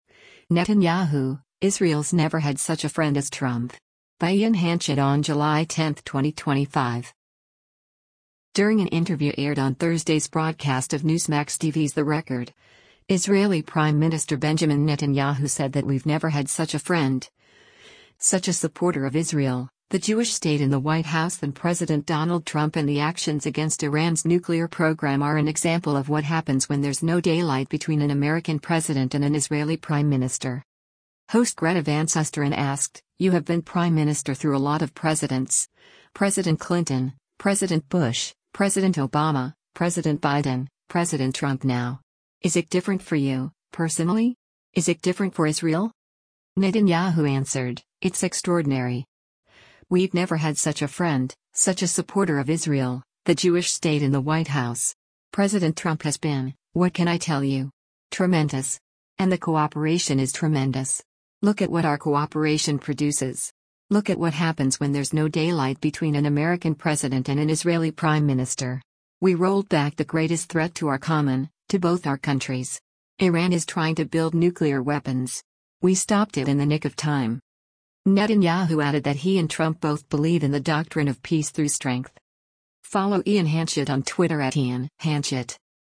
During an interview aired on Thursday’s broadcast of Newsmax TV’s “The Record,” Israeli Prime Minister Benjamin Netanyahu said that “We’ve never had such a friend, such a supporter of Israel, the Jewish state in the White House” than President Donald Trump and the actions against Iran’s nuclear program are an example of “what happens when there’s no daylight between an American president and an Israeli prime minister.”